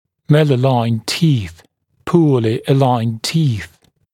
[ˌmælə’laɪnd tiːθ] [puəlɪ ə’laɪnd tiːθ] [ˌмэлэ’лайнд ти:с] [пуэли э’лайнд ти:с] неровные зубы; аномально расположенные зубы